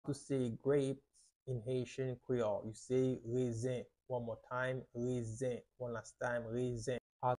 How to say “Grapes” in Haitian Creole – “Rezen” pronunciation by a native Haitian Teacher
“Rezen” Pronunciation in Haitian Creole by a native Haitian can be heard in the audio here or in the video below:
How-to-say-Grapes-in-Haitian-Creole-–-Rezen-pronunciation-by-a-native-Haitian-Teacher.mp3